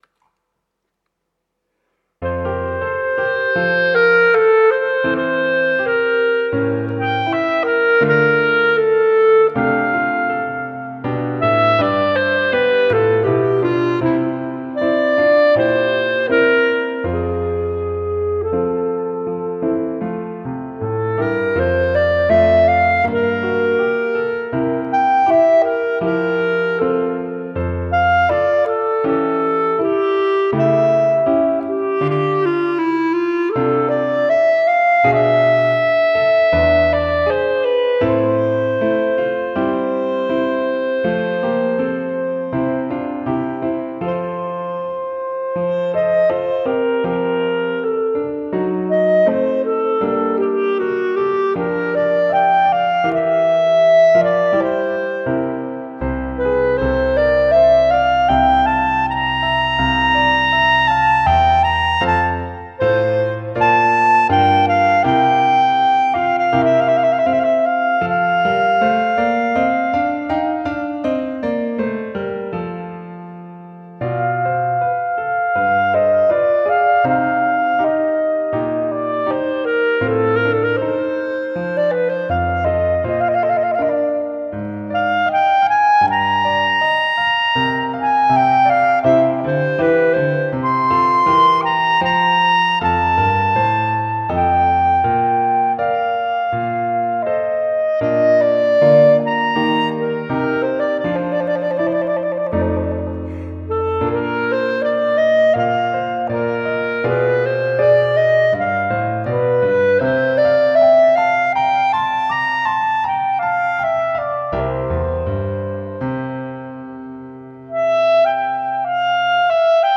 Scored for Bb Clarinet and Piano